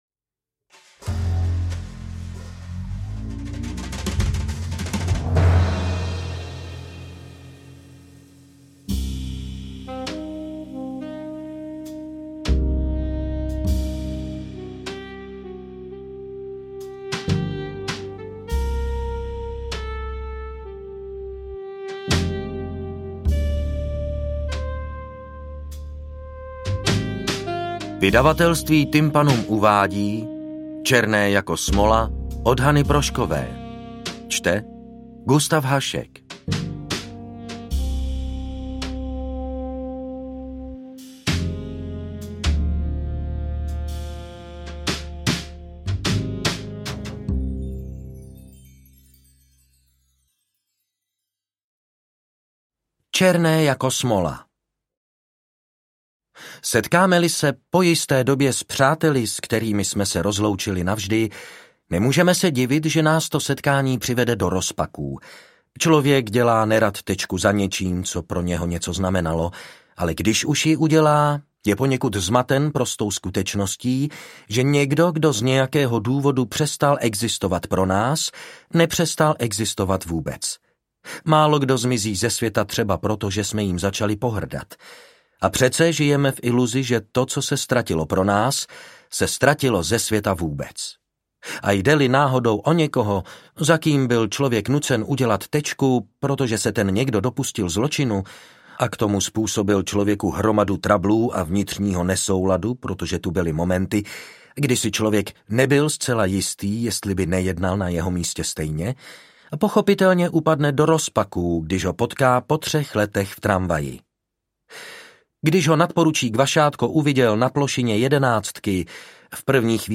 AudioKniha ke stažení, 31 x mp3, délka 12 hod. 10 min., velikost 669,1 MB, česky